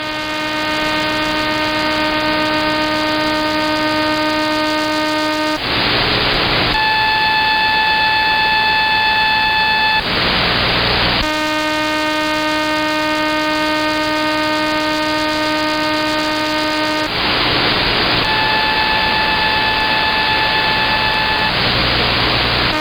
сигнал на 28600 кГц